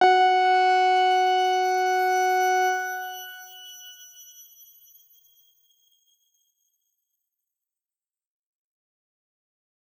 X_Grain-F#4-mf.wav